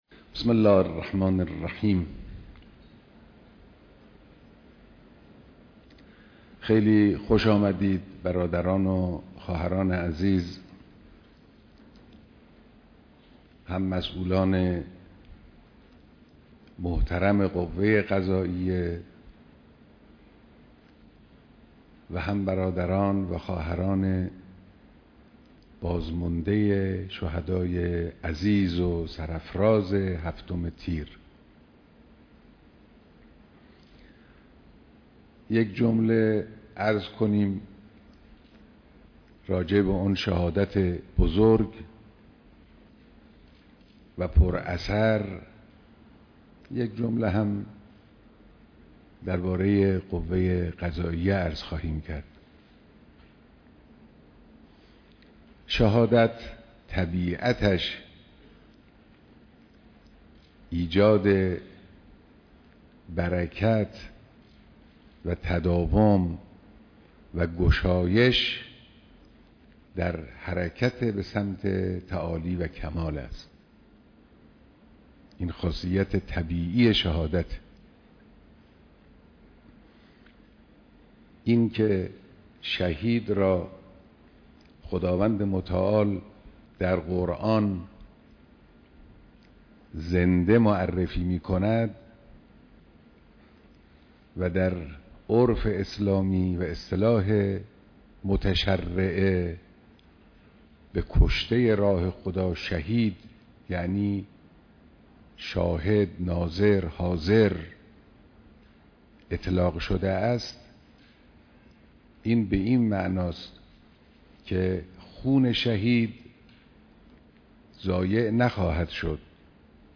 دیدار رئیس و مسئولان قوه قضاییه